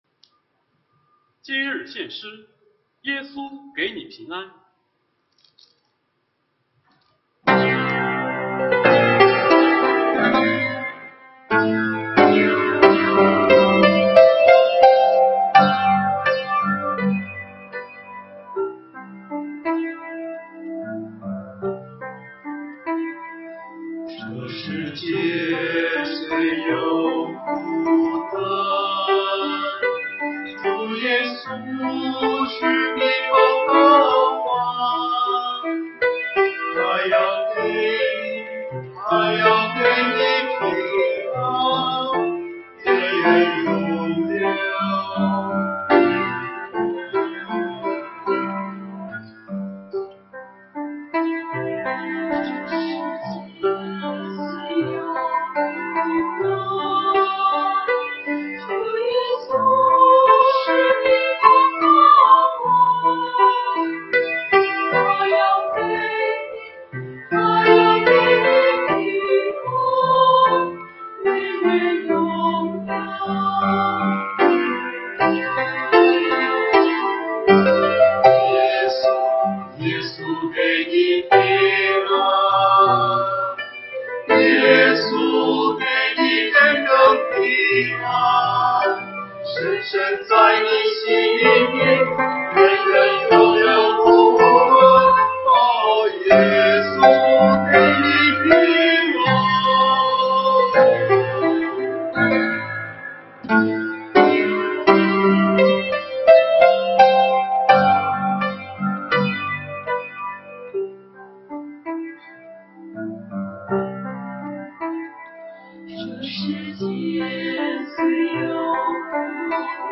团契名称: 青年、迦密诗班 新闻分类: 诗班献诗 音频: 下载证道音频 (如果无法下载请右键点击链接选择"另存为") 视频: 下载此视频 (如果无法下载请右键点击链接选择"另存为")